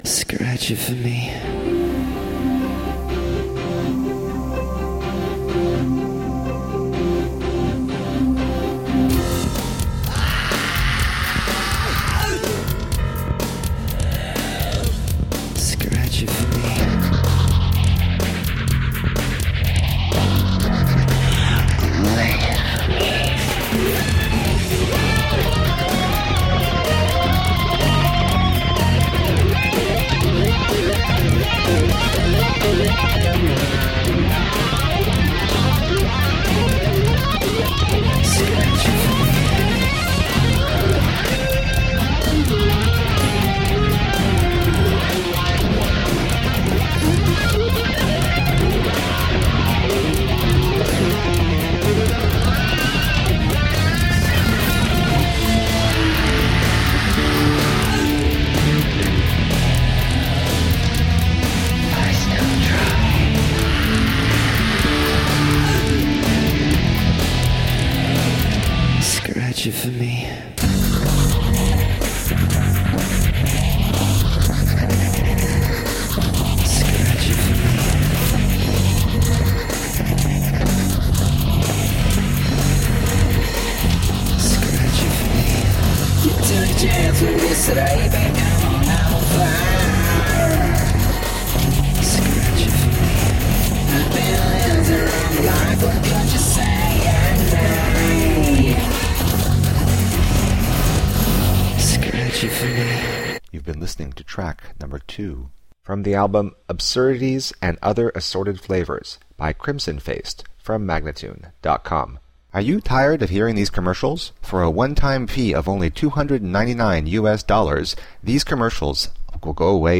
Guitar-edged industrial electrorock.